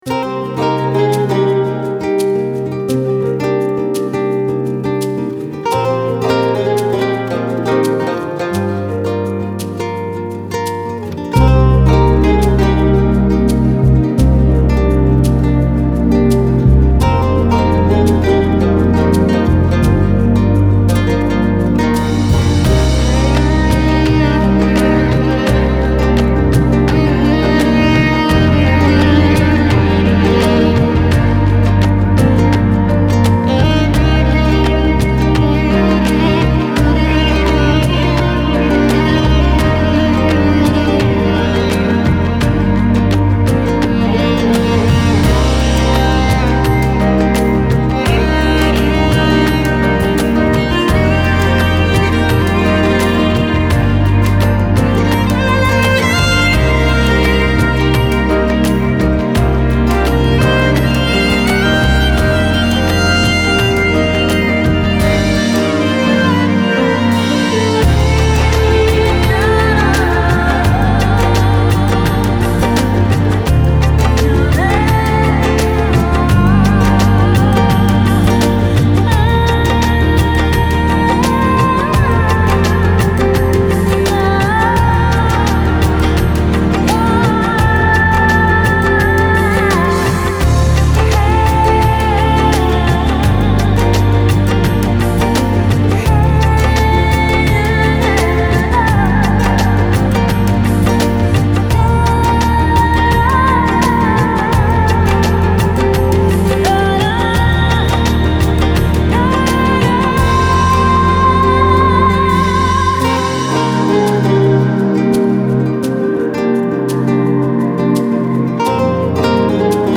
Медитативная музыка Релакс Нью эйдж New Age Музыка релакс